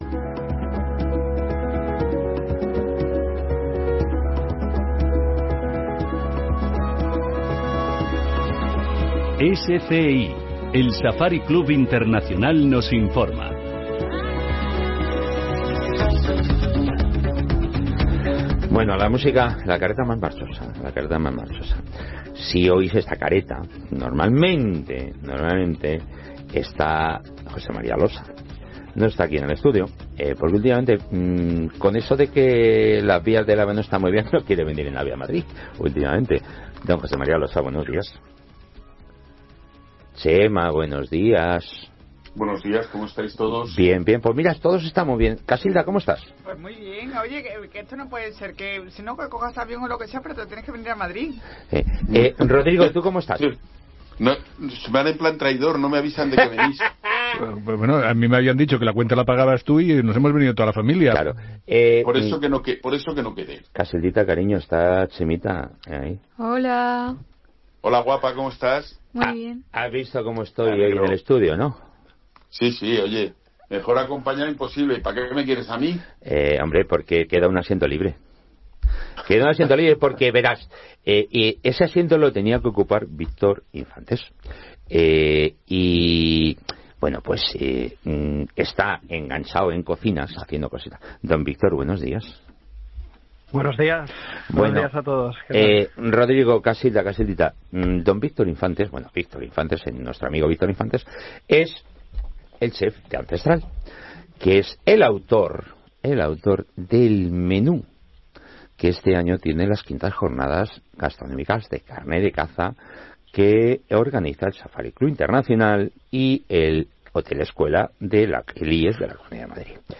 Esta entrevista radiofónica, organizada por el Safari Club Internacional (SCI) y el Hotel Escuela de la Comunidad de Madrid, destaca la importancia de la carne de caza en la gastronomía española.